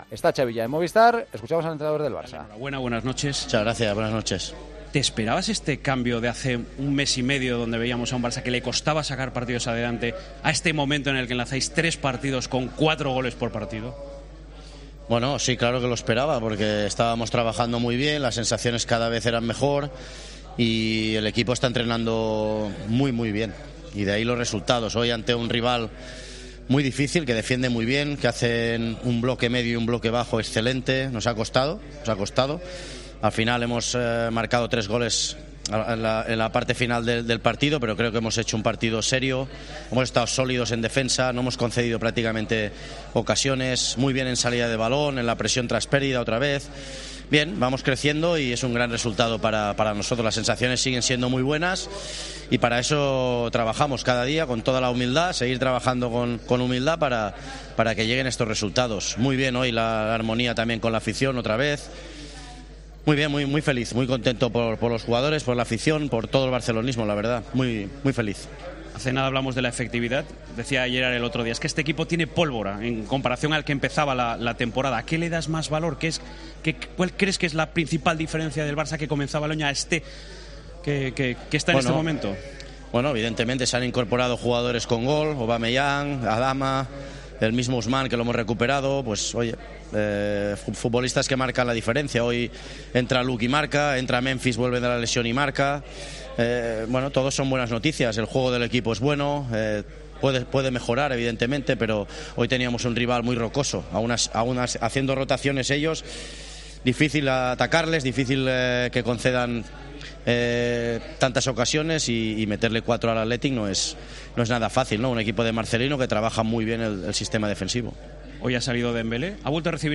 TRAS EL PARTIDO
El entrenador del FC Barcelona ha asegurado en Movistar+ el buen trabajo de su equipo en un partido "muy serio" de los suyos destacando los cambios producidos en la segunda parte.